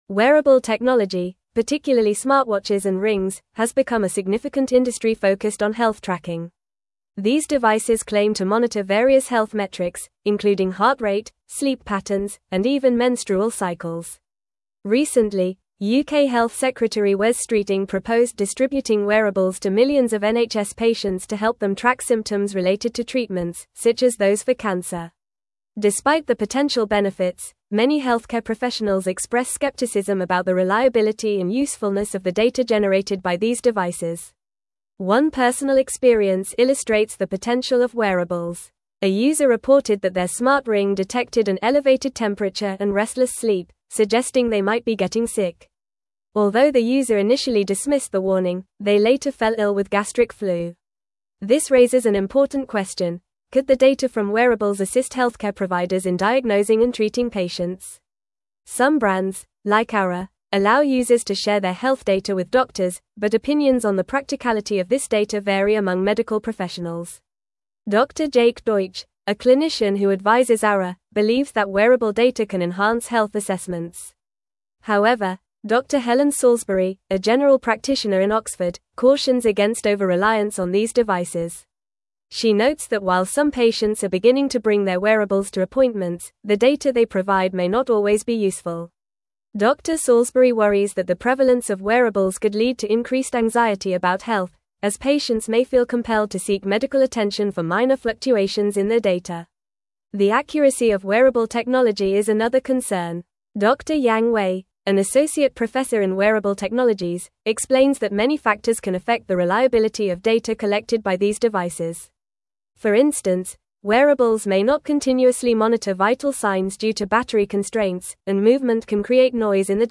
Fast
English-Newsroom-Advanced-FAST-Reading-Wearable-Technologys-Impact-on-Healthcare-Benefits-and-Concerns.mp3